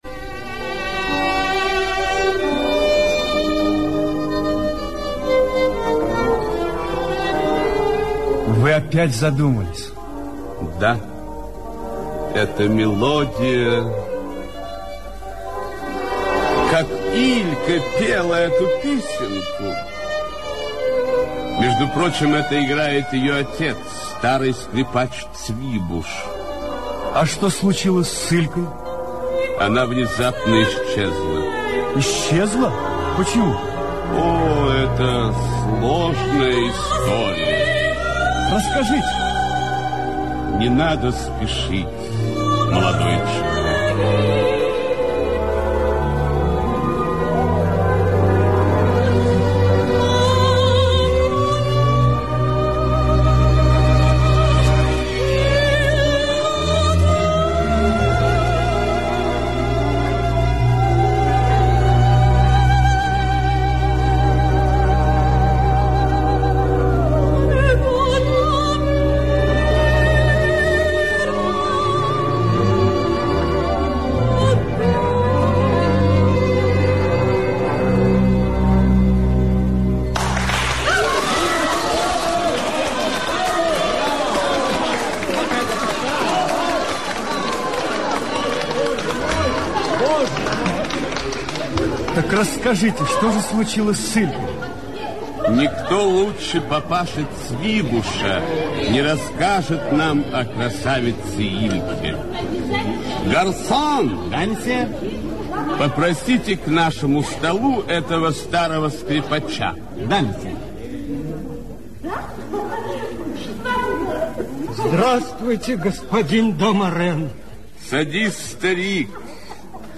Радиопостановка.
Использована венгерская народная музыка.